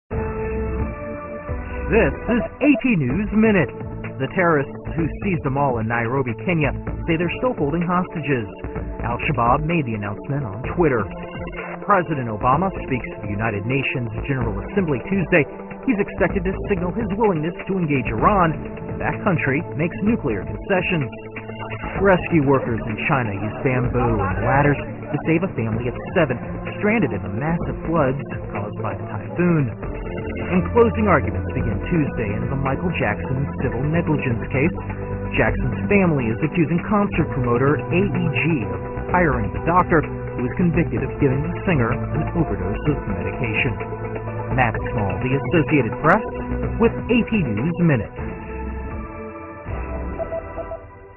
在线英语听力室美联社新闻一分钟 AP 2013-10-01的听力文件下载,美联社新闻一分钟2013,英语听力,英语新闻,英语MP3 由美联社编辑的一分钟国际电视新闻，报道每天发生的重大国际事件。电视新闻片长一分钟，一般包括五个小段，简明扼要，语言规范，便于大家快速了解世界大事。